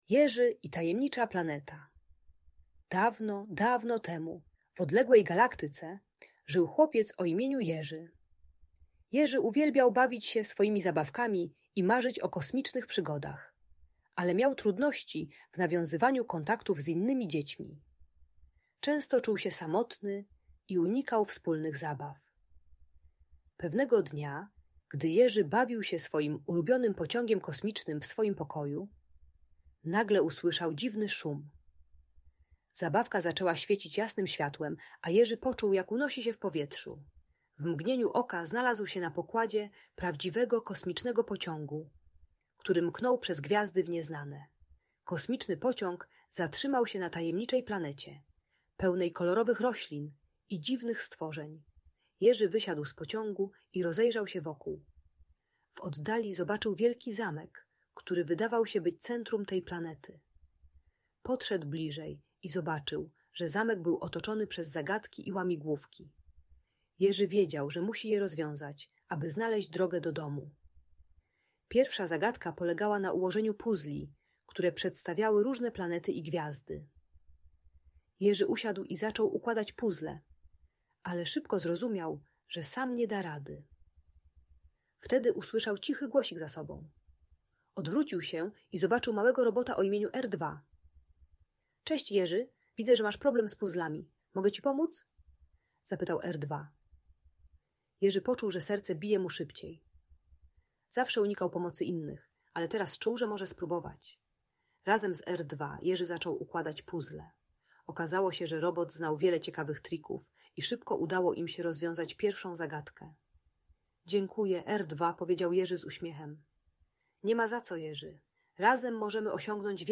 Jerzy i Tajemnicza Planeta - Lęk wycofanie | Audiobajka
Uczy techniki małych kroków w budowaniu relacji - od przyjęcia pomocy po wspólną zabawę. Audiobajka o nieśmiałości i otwieraniu się na przyjaźń.